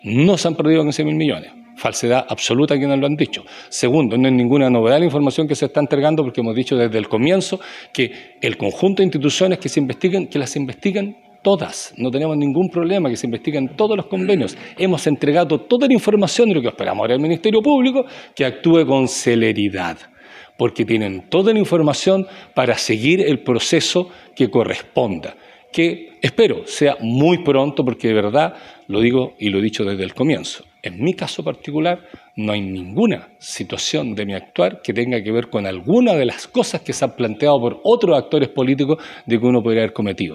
Al respecto, el Gobernador Patricio Vallespín, pidió la máxima seriedad de quienes opinan en la materia, puntualizando que hay 8 mil millones de pesos recuperados y lo restante fue ejecutado.